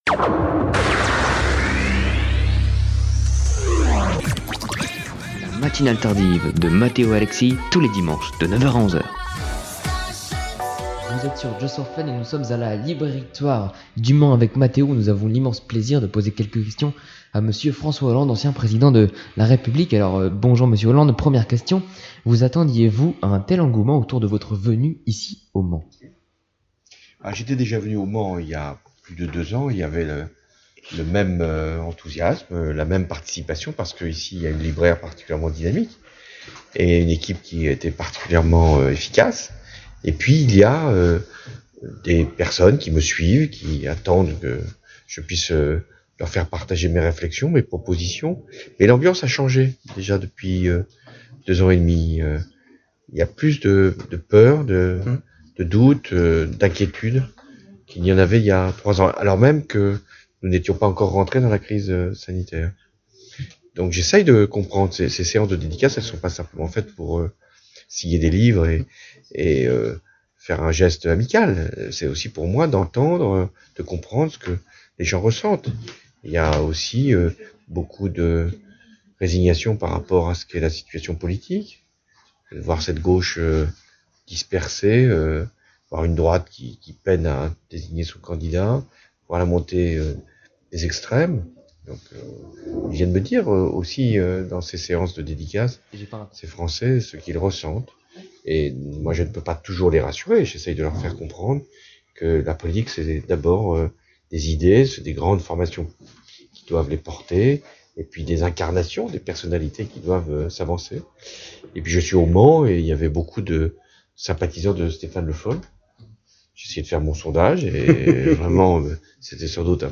Interview de François Hollande
François Hollande était l'invité de la Matinale Tardive, le dimanche 7 novembre. Il est revenu sur son mandat présidentiel et a livré quelques impressions sur la situation actuelle.